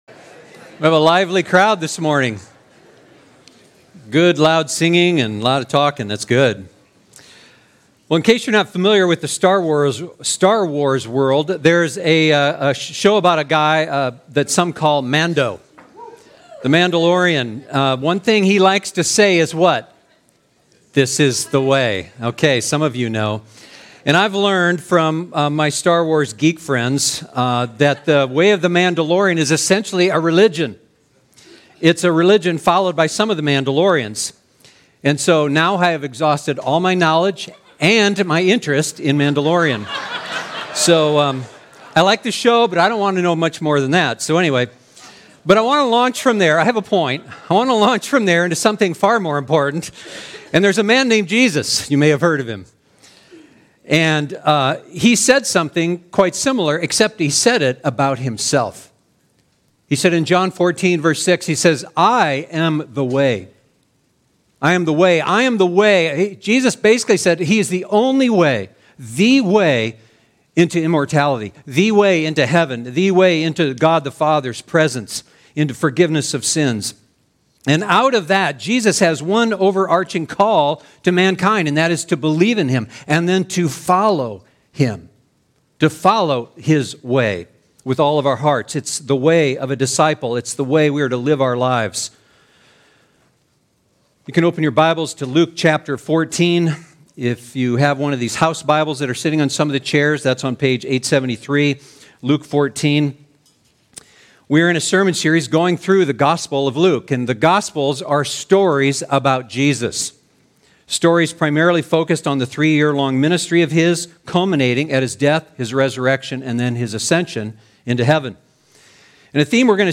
The way of a disciple of Jesus—the life and heart of a disciple—is not intuitive due to our sinful tendencies and the world’s influences, but it is beautiful and glorious. This Sunday in our sermon we will examine Jesus’ surprising words as he calls us to be true disciples.
SERMON